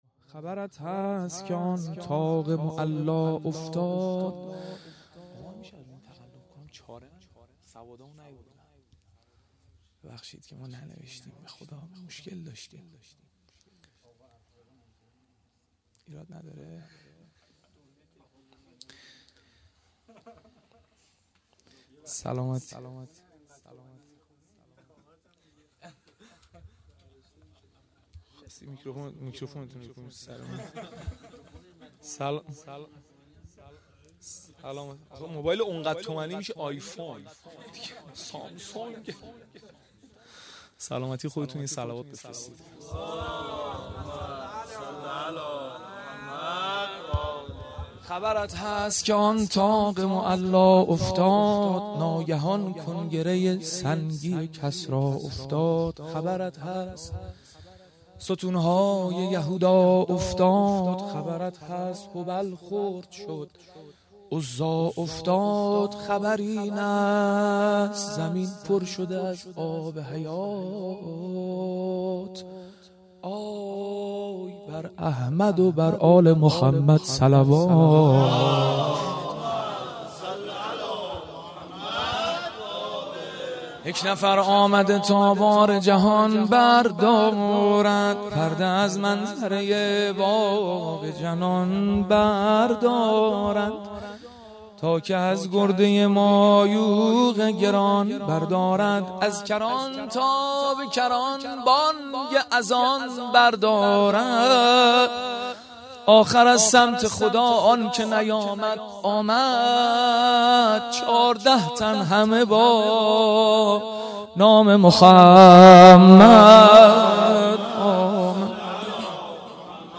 جشن میلاد حضرت رسول (ص) و امام جعفر صادق (ع) 1395
شعر «1»